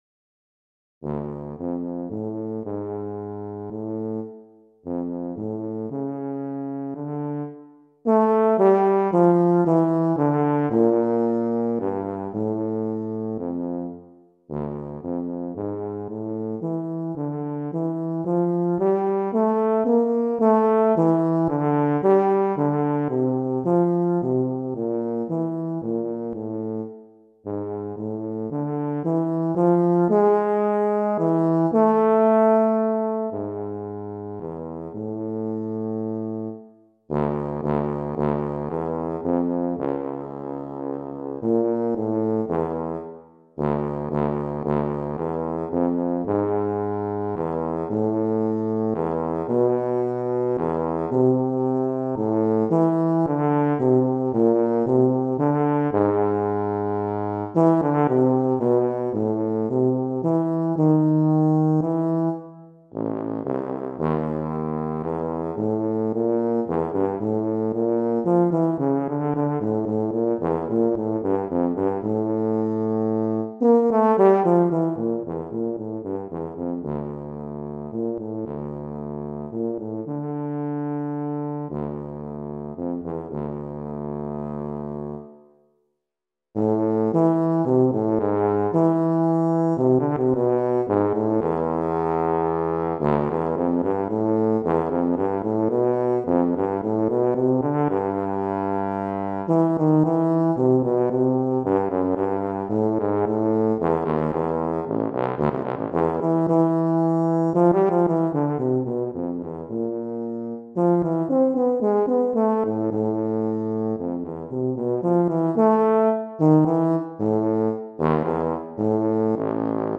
Voicing: Tuba Methods/Studies/Etudes